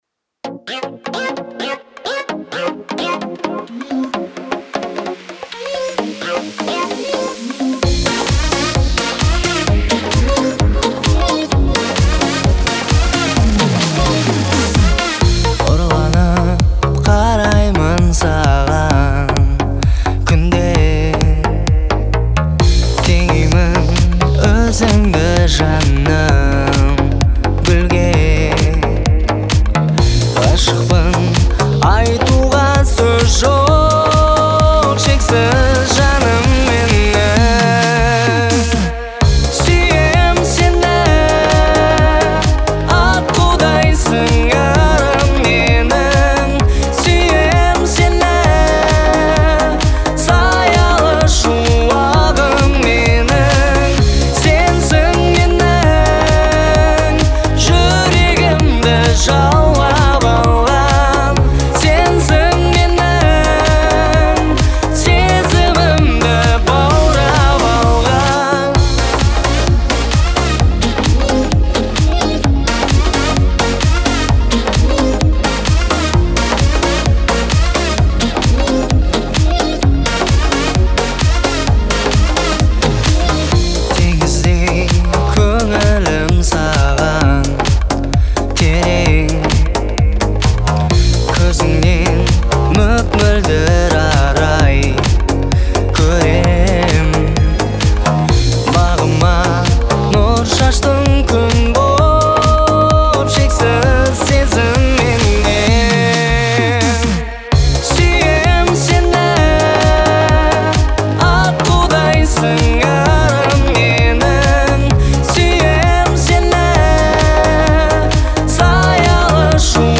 это романтическая песня в жанре поп